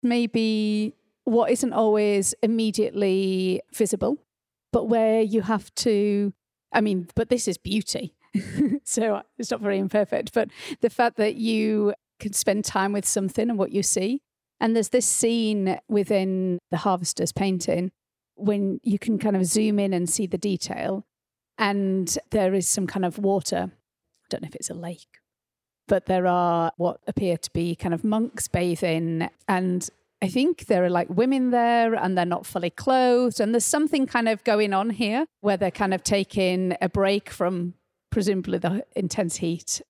Listen as they describe the artwork in their own words.